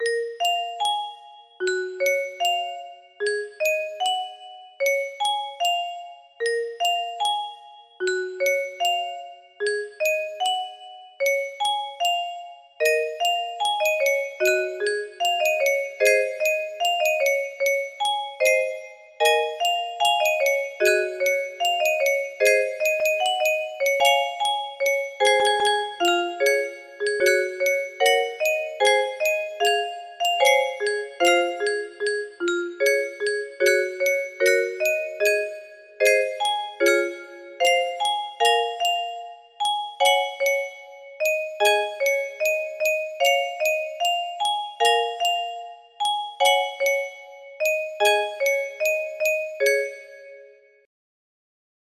Dear music box melody